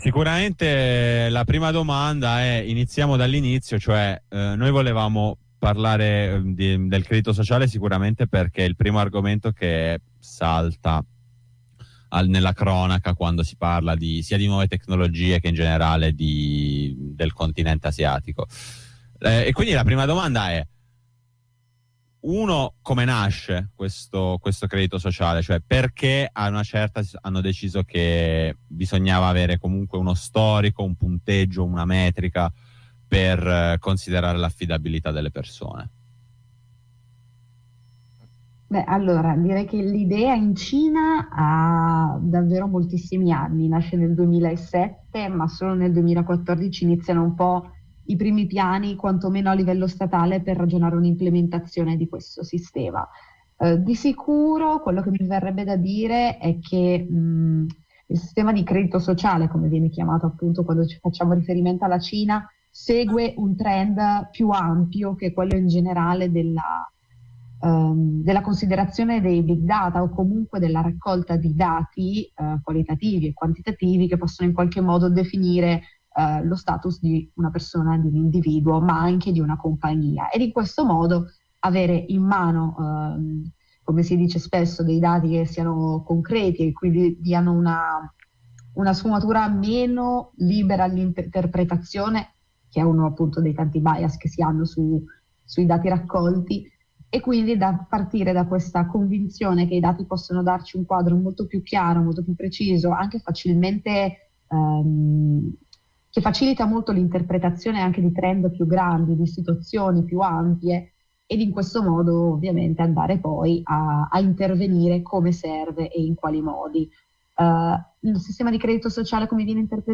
Qui l’intervista integrale:
intervista-integrale-escopost.mp3